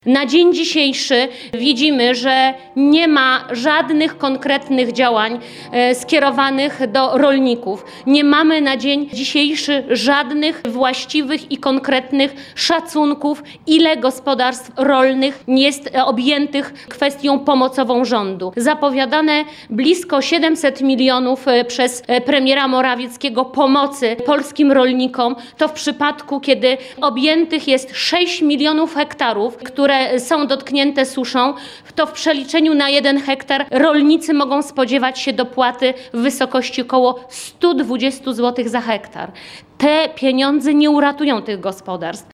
Apel do rządu i premiera Mateusza Morawieckiego wystosowała w poniedziałek (06.08.18) podczas konferencji prasowej w swoim biurze poselskim Bożena Kamińska, posłanka Platformy Obywatelskiej. Chodzi o podjęcie natychmiastowych działań mających na celu pomoc rolnikom poszkodowanym przez panującą suszę.